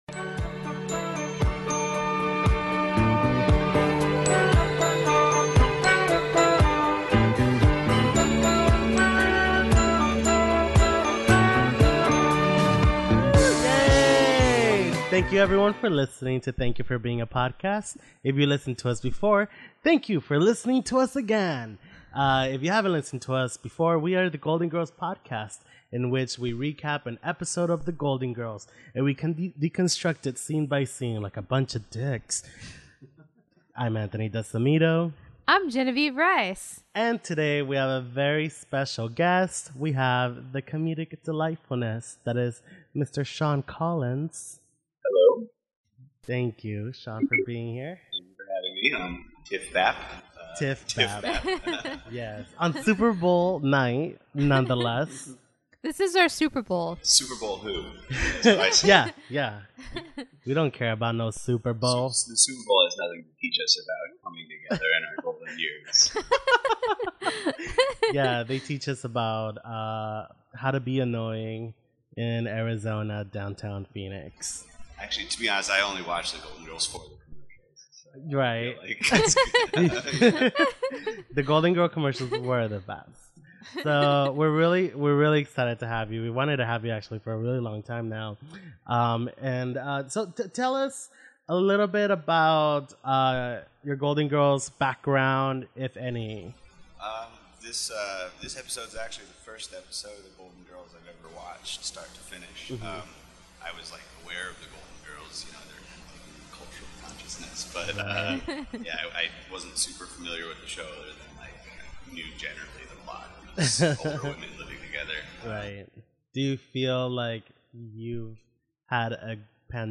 if you can make it past 3 minutes it continues to get better.